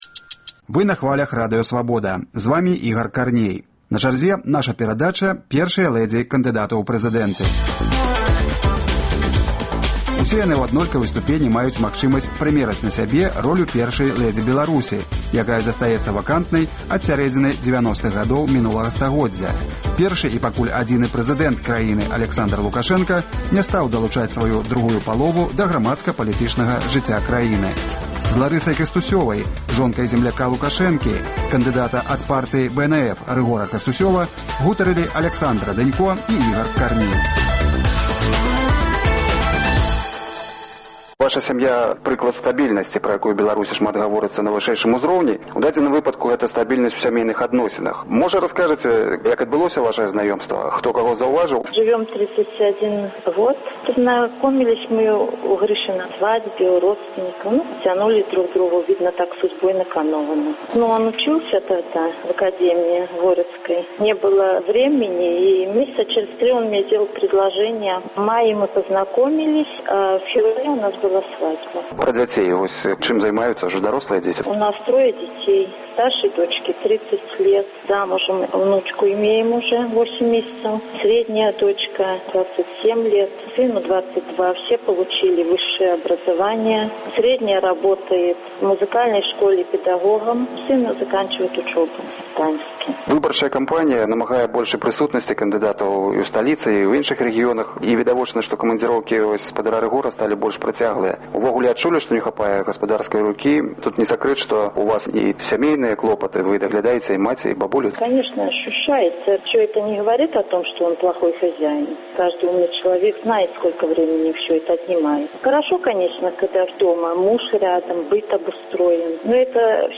Мы працягваем сэрыю гутарак з жонкамі і нявестамі кандыдатаў у прэзыдэнты, якія ў прэзыдэнцкай кампаніі дзеляць са сваімі мужчынамі ўсе цяжкасьці выбарчых баталіяў. Ці падзяляеце вы палітычныя погляды вашага мужа?